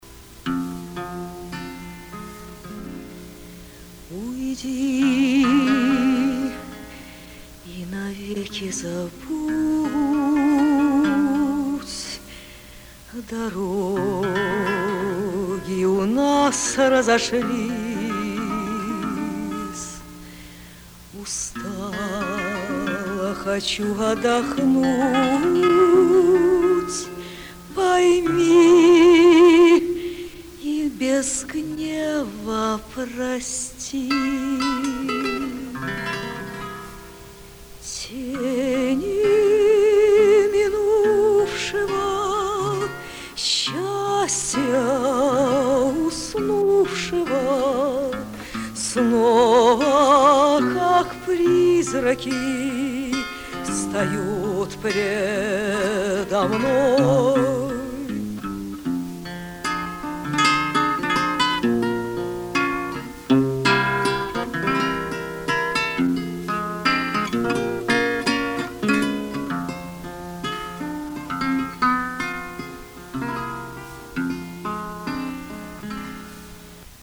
Романсы на стихи Ф.И. Тютчева: